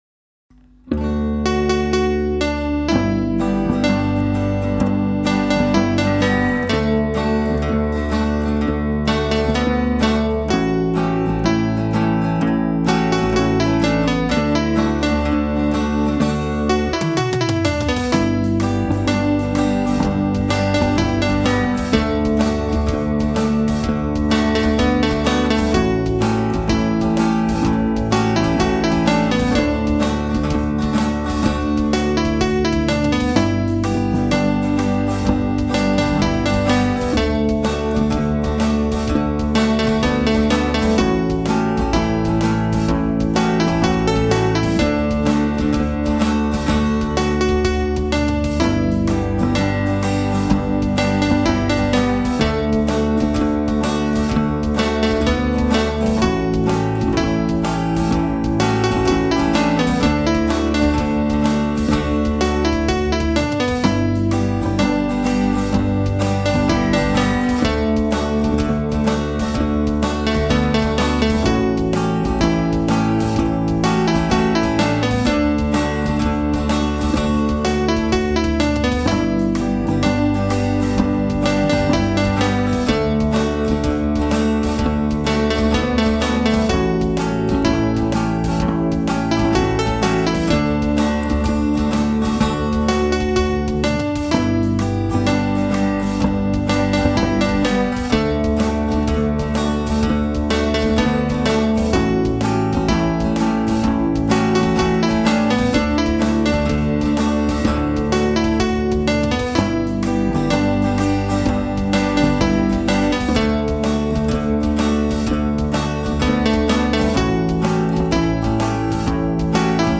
Midnight-Special-Ges-Melodie.mp3